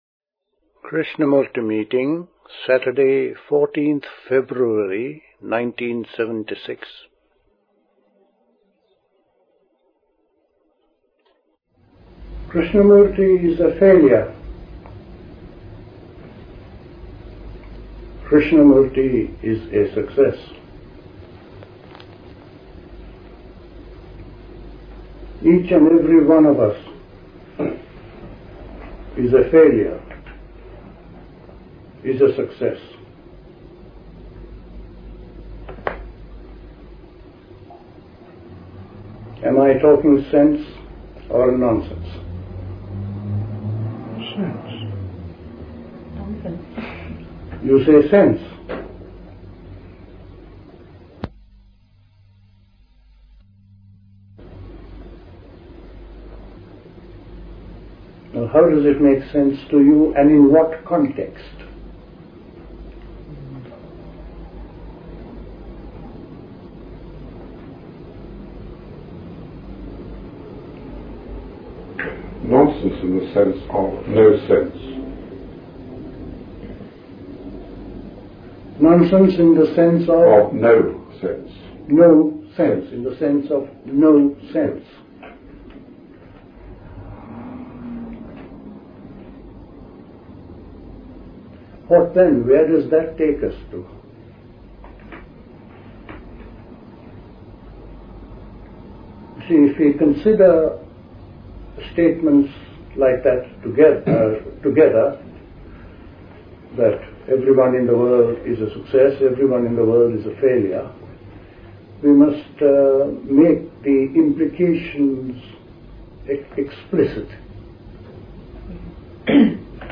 Recorded at a Krishnamurti meeting.